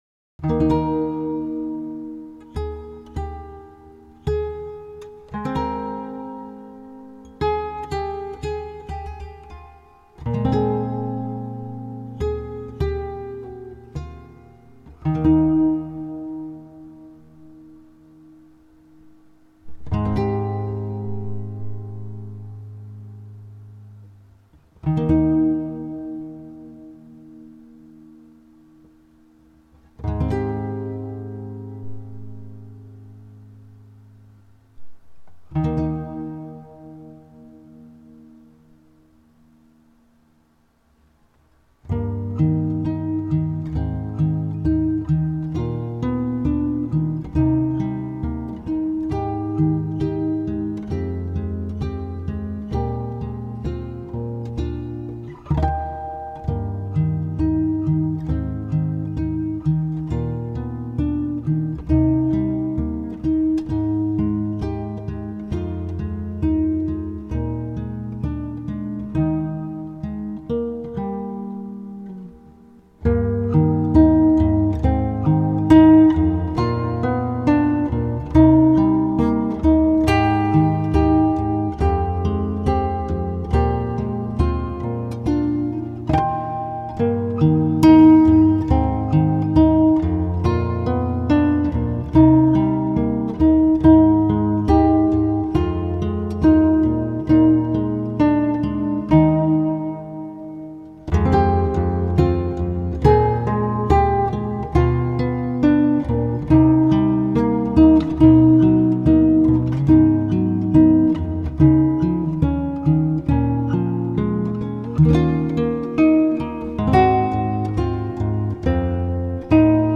音程変えて遊んでたら、なんか心地いい音になったのでUP
ピッチ4度下げで加工。曲は｢放課後の音楽室｣
チューニングを4度下げたギターで弾いても、この響きにはならない。
つまり ｢本来あり得ない音｣ のハズなんだけど、耳には心地よく 自然に入ってくる